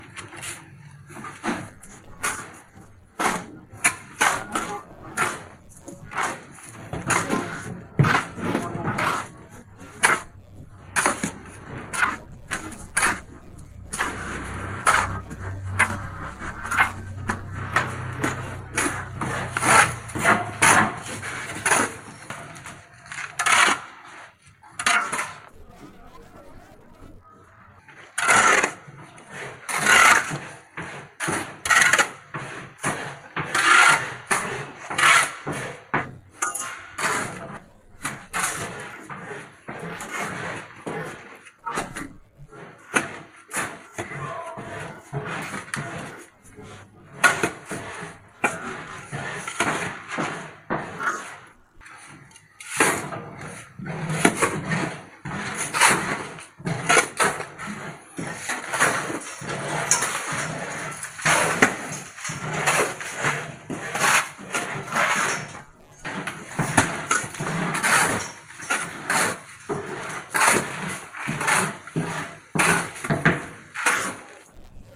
Tiếng Phụ Hồ, trộn Vữa, trộn Cát, Sạn Bê tông… bằng Tay
Thể loại: Tiếng động
Description: Tiếng phụ hồ trộn vữa, cát, sỏi bê tông bằng tay vang lên sột soạt, đều đặn, hòa quyện âm thanh khô khốc của xẻng chạm cát, sỏi lạo xạo và vữa quánh dẻo. Âm thanh mộc mạc, chân thực, gợi hình công việc xây dựng nhọc nhằn... cho hiệu ứng âm thanh (sound effect) hoặc chỉnh sửa video, mang đến cảm giác gần gũi, chân thực, rất quen thuộc ở Việt Nam.
tieng-phu-ho-tron-vua-tron-cat-san-be-tong-bang-tay-www_tiengdong_com.mp3